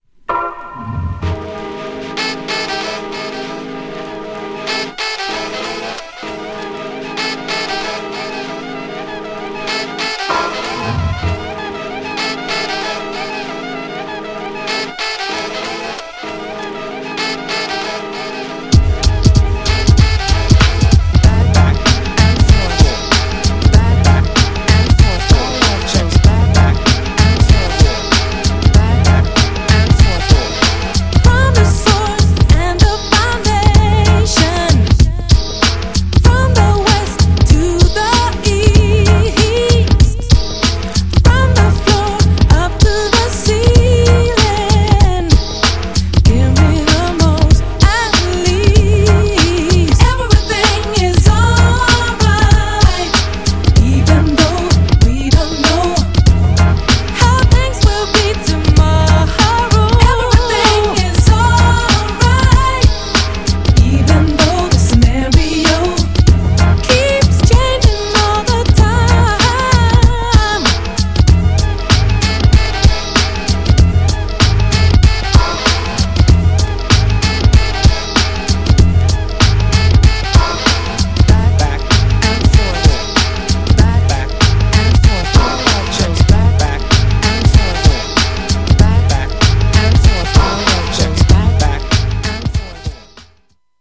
アブストラクトなアレンジがカッコ良いUK R&B!!
GENRE House
BPM 121〜125BPM